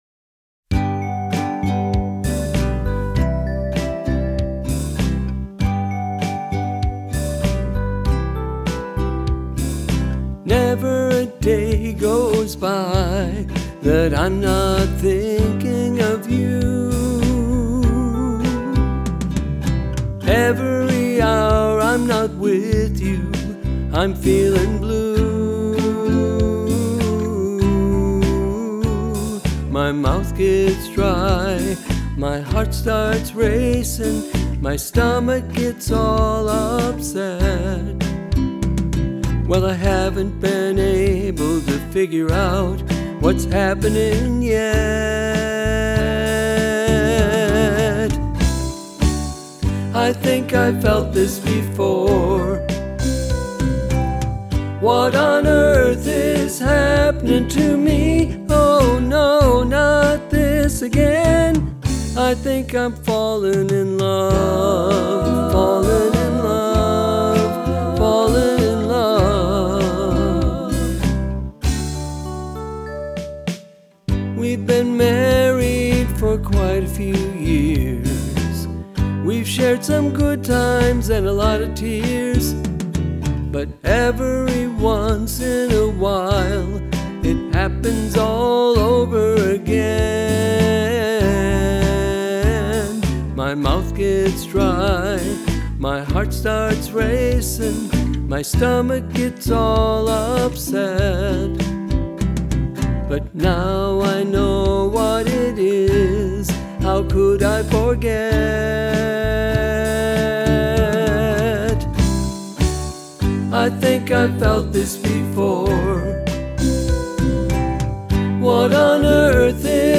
It’s a rather funny song, but it declares my love too.